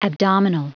Prononciation du mot abdominal en anglais (fichier audio)
Prononciation du mot : abdominal